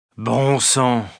Play AoE2 Taunt FR 24 - Dadgum - SoundBoardGuy
Play, download and share AoE2 Taunt FR 24 – dadgum original sound button!!!!